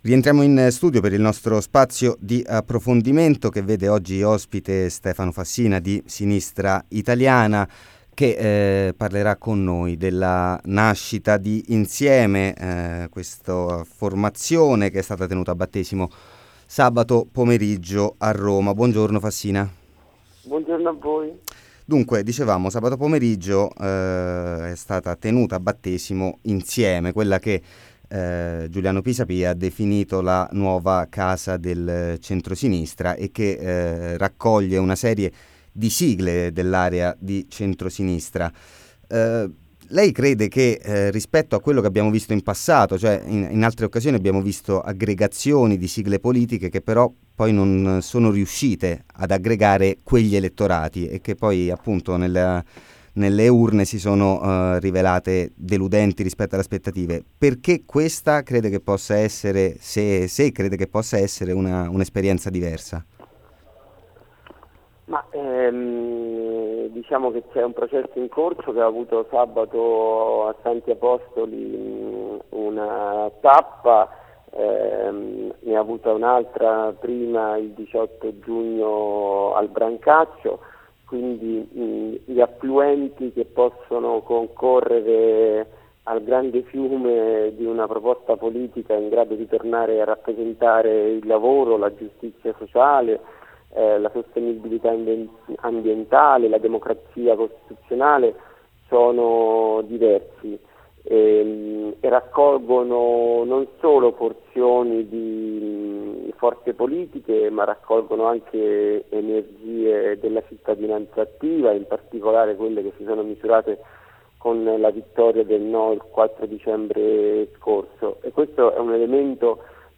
Intervista a Stefano Fassina | Radio Città Aperta